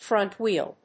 アクセントfrónt‐whéel